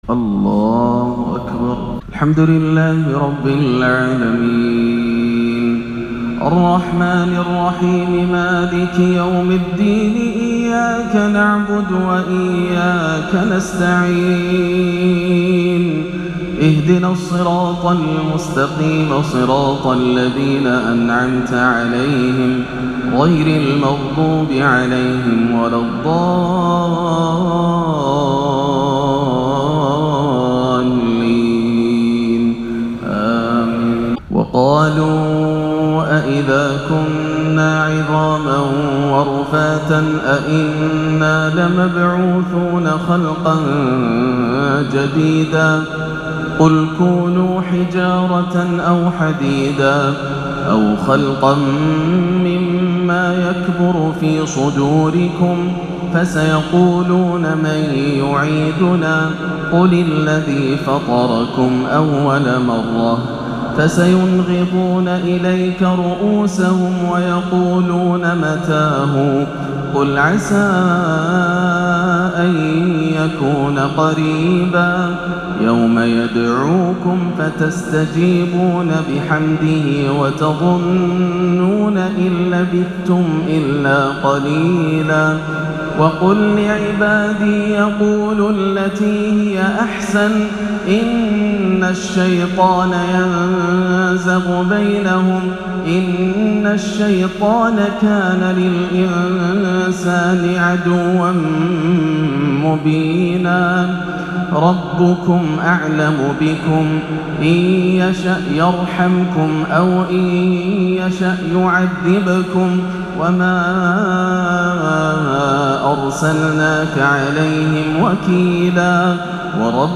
(إن عبادي ليس لك عليهم سلطان) تلاوة بااكية فاقت الوصف من سورة الإسراء - عشاء الخميس 13-6 > عام 1439 > الفروض - تلاوات ياسر الدوسري